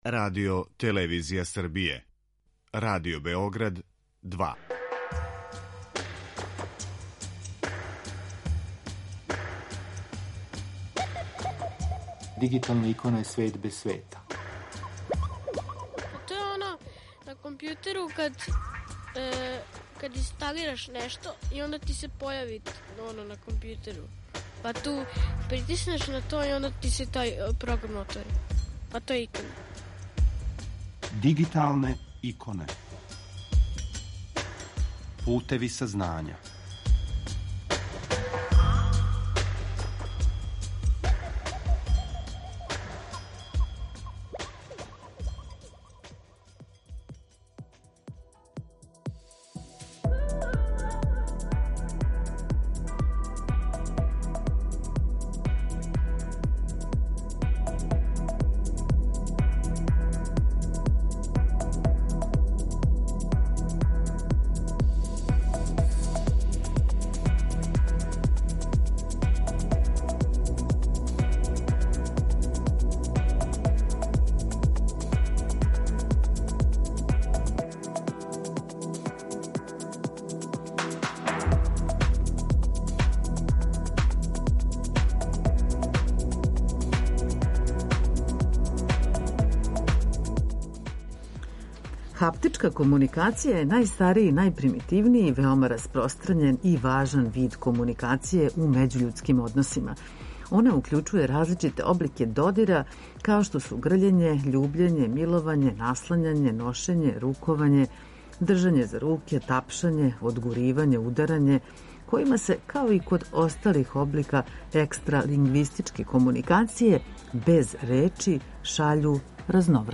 Са нама уживо